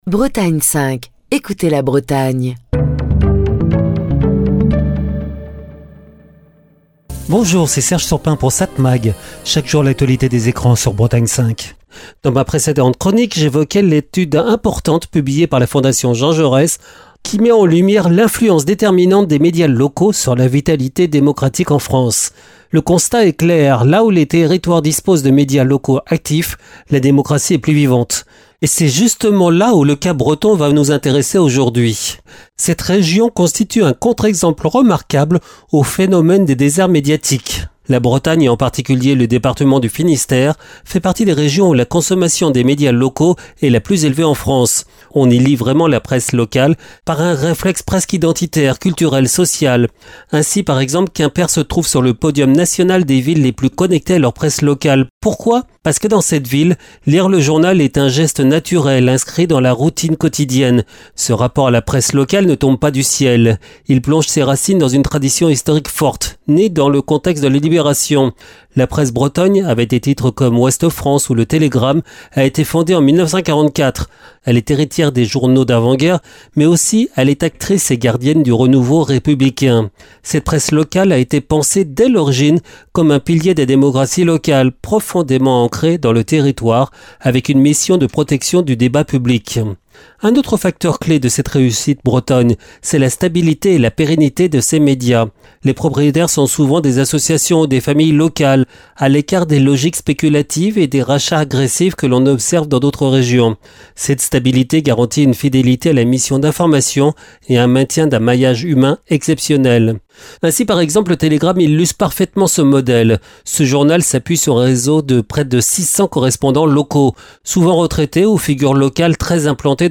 Chronique du 27 novembre 2025.